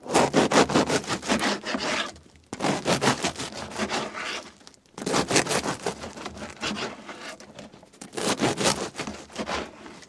Звуки хлеба
Звук разрезания батона на части